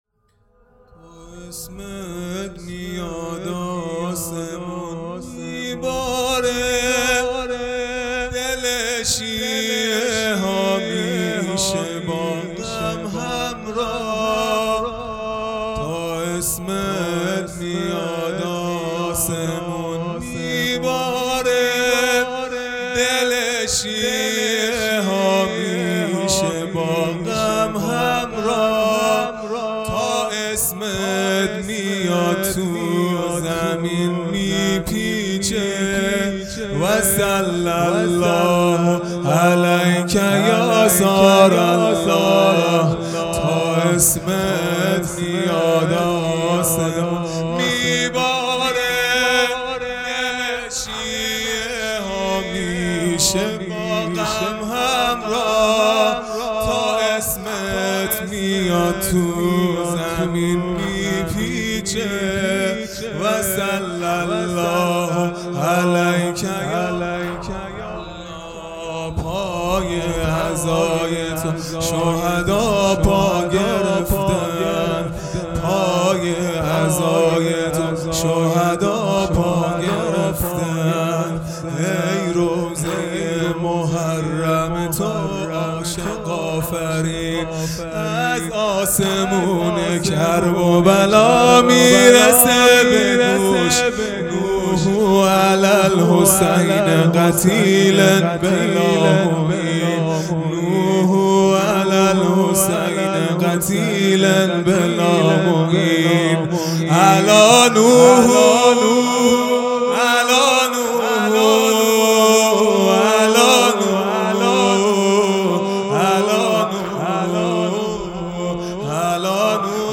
خیمه گاه - هیئت بچه های فاطمه (س) - واحد سنگین | تا اسمت میاد آسمون میباره
دهه اول محرم الحرام ۱۴۴٢ | شب دوم